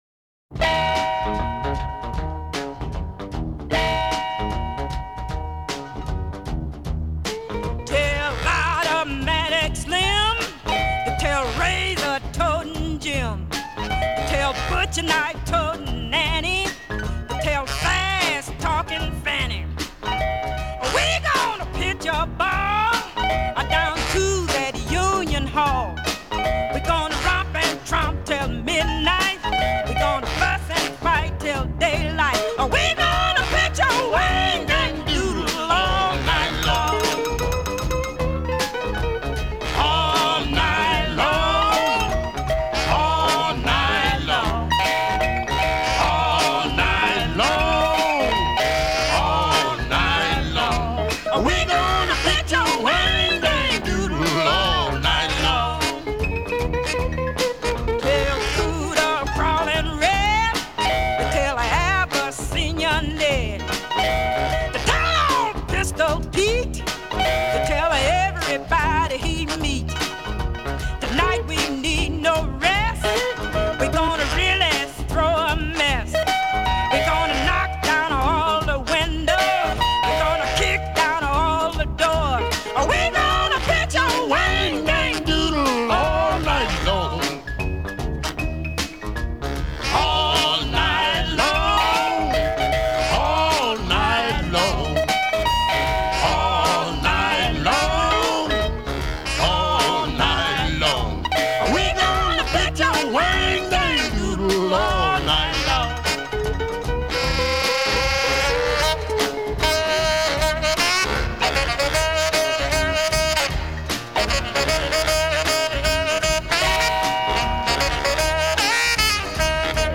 The party sounds rough and exciting:
guitar